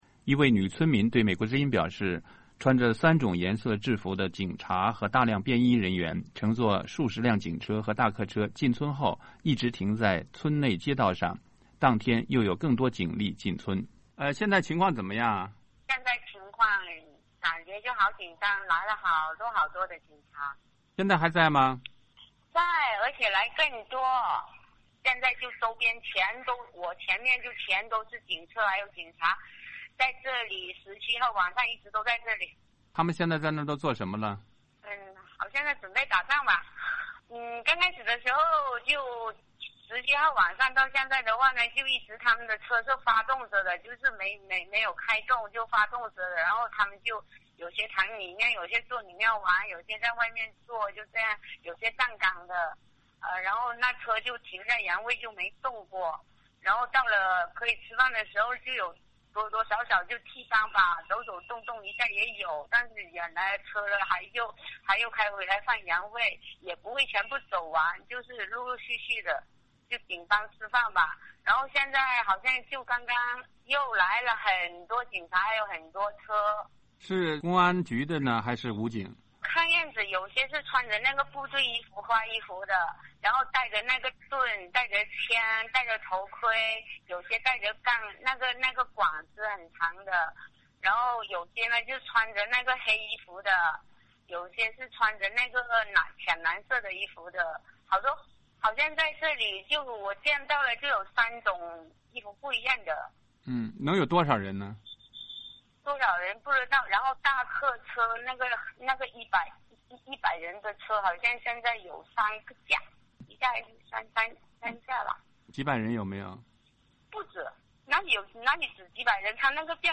一位女村民对美国之音表示，穿着三种颜色制服的警察和大量便衣人员，乘坐数十辆警车和大客车进村后，一直停在村内街道上，当天又有更多警力进村。
一位男性村民对美国之音简短地表示，他们正在开村民大会。